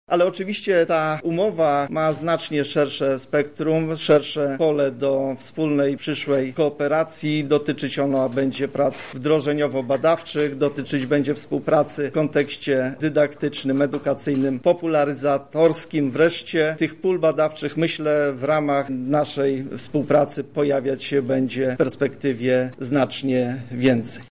• mówi rektor UMCS profesor Radosław Dobrowolski.